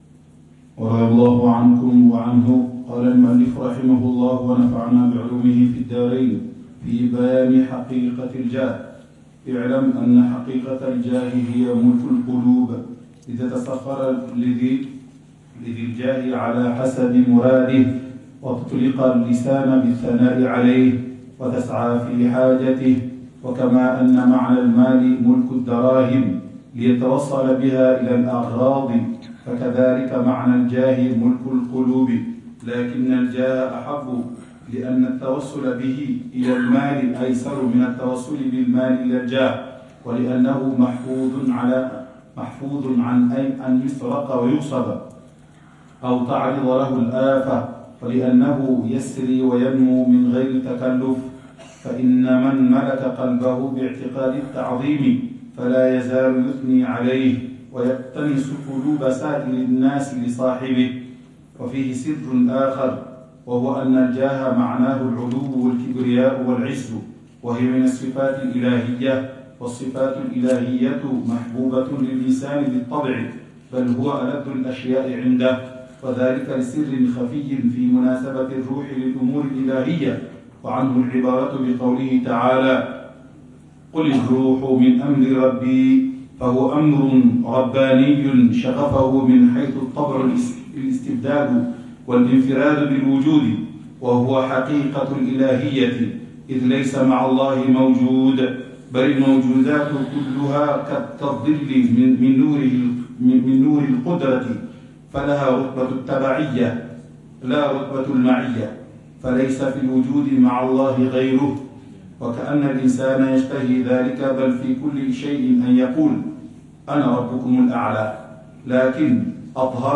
الدرس ال26 في شرح الأربعين في أصول الدين | الرعونة وحُبّ الجاه (2): توهّم العلو بالناس… أم رفعة بالعبوديّة؟